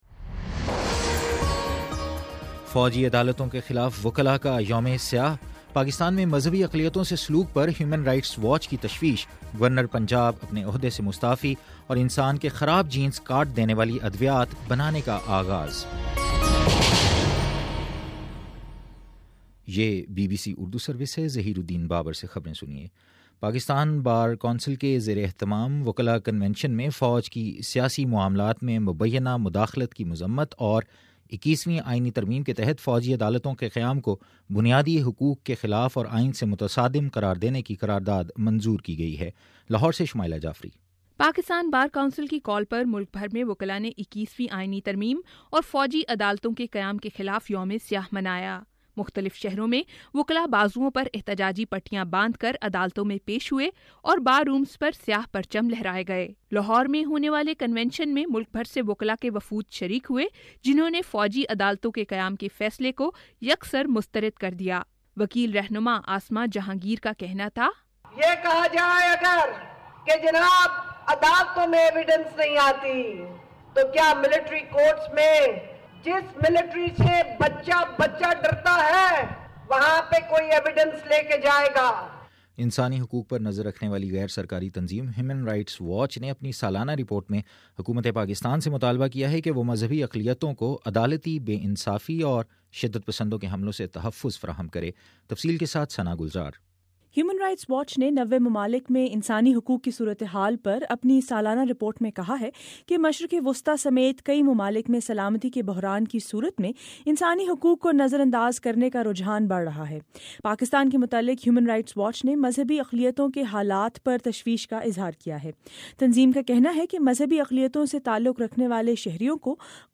جنوری 29: شام چھ بجے کا نیوز بُلیٹن